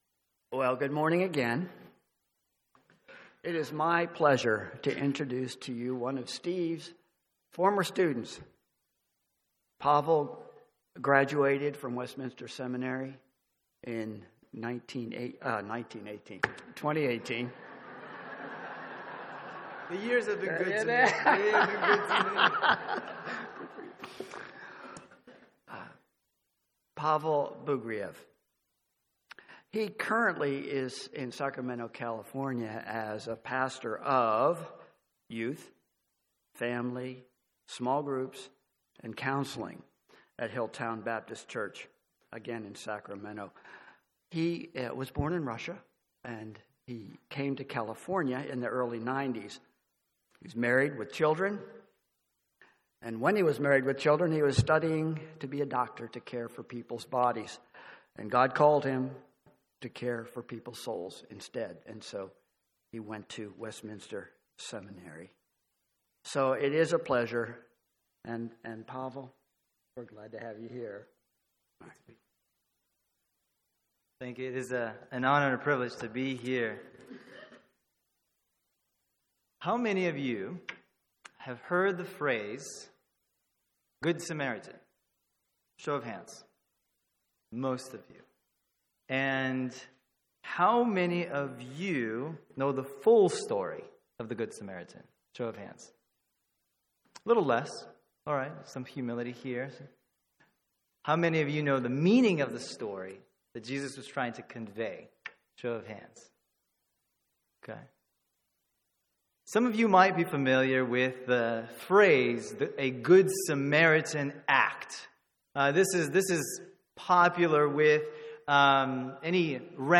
Sermons on Luke 10:25-37 — Audio Sermons — Brick Lane Community Church